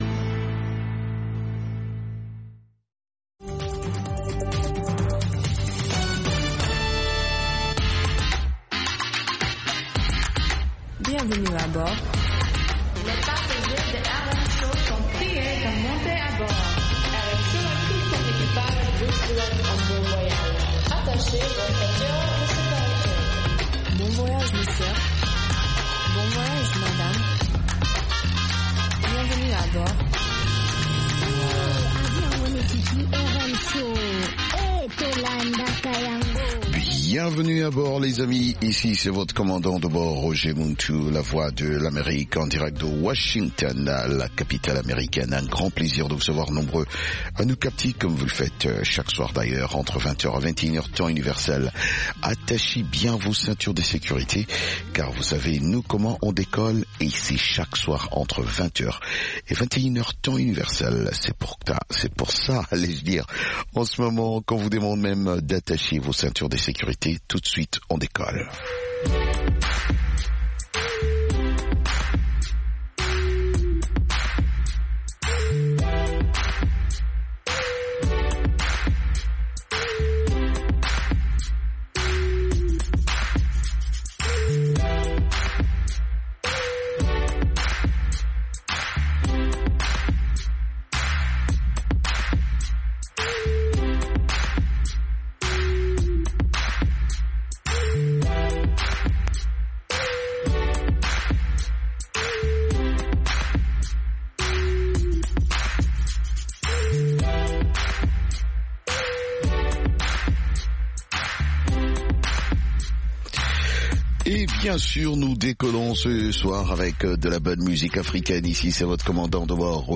Afro Music